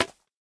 Minecraft Version Minecraft Version latest Latest Release | Latest Snapshot latest / assets / minecraft / sounds / block / bamboo / place2.ogg Compare With Compare With Latest Release | Latest Snapshot